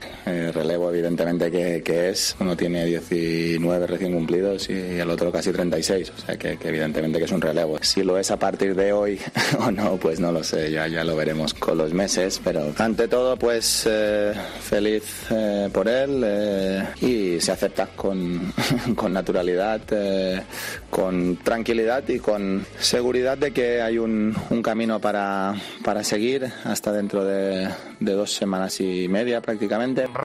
"Felicito a Alcaraz. Relevo evidentemente que es, uno tiene 19 y el otro casi 36 años, es un relevo. Si lo es a partir de hoy o no ya lo veremos con los meses. Estoy feliz por él, ha estado mejor que yo en varias facetas del juego. Yo necesito mejorar como he ido hablando estos días. No es nada que no entrara dentro de la lógica y se acepta", dijo en rueda de prensa.